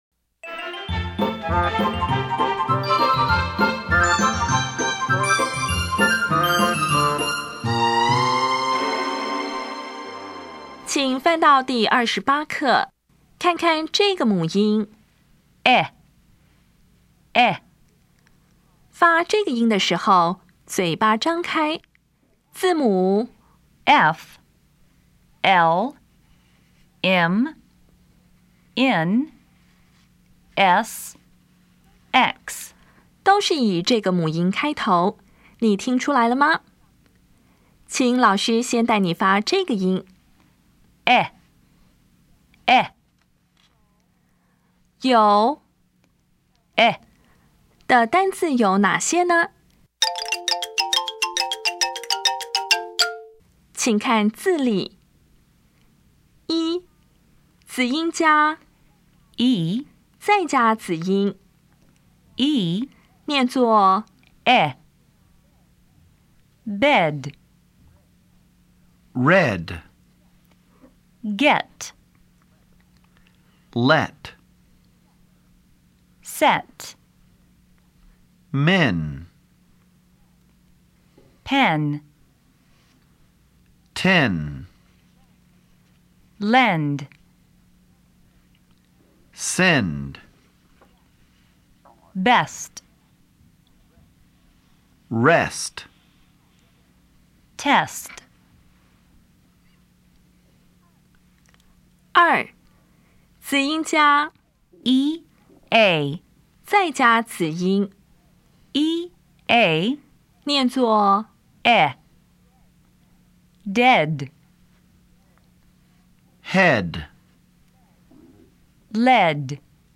当前位置：Home 英语教材 KK 音标发音 母音部分-1: 短母音 [ɛ]
音标讲解第二十八课
比较[ɪ] [ɛ]